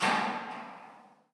Abrir una puerta con reverberación
reverberar
Sonidos: Hogar